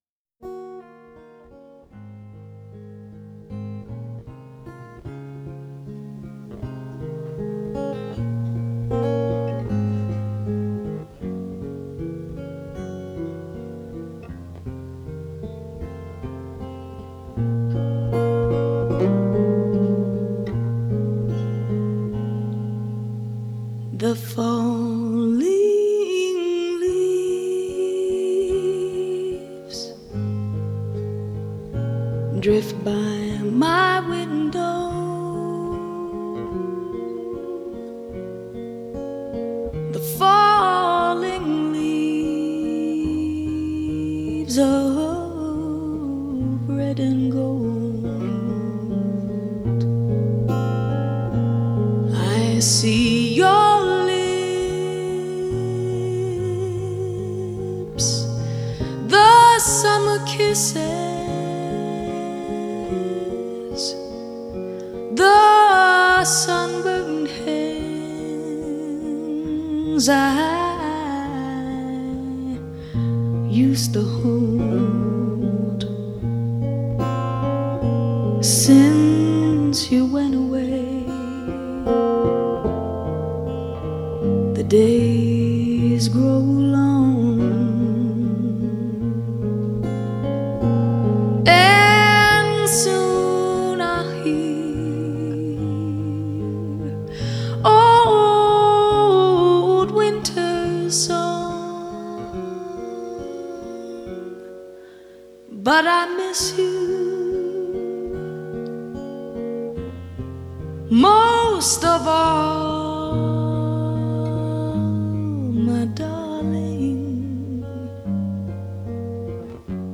像清泉一样轻轻流入心底，让人欲罢不能
我是边听边卡的听完的，嗓音很好啊，很有张力，控制的也很好，可惜没有图片看看真人在世时什么样子！
就是喜欢这样的曲调 宁静带点感伤 在深夜很合适一听
歌手的声音不错  可惜了